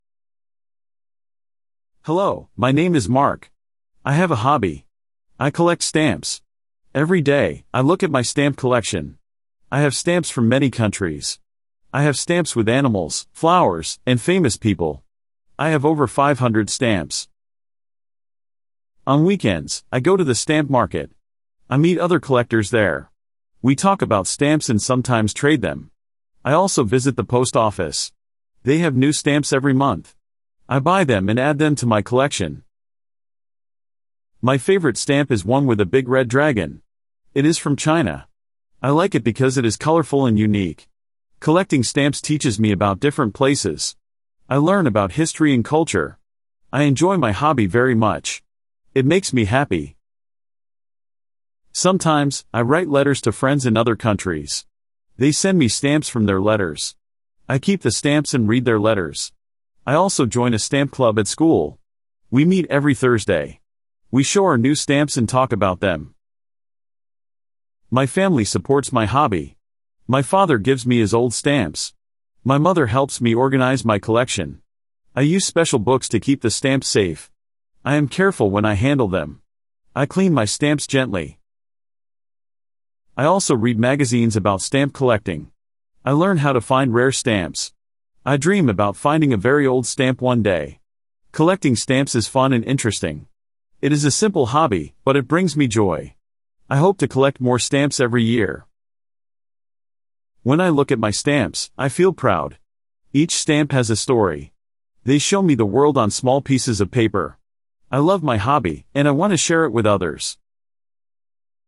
Beginner Listening Practice
A young collector describes his stamp hobby and how he builds his collection.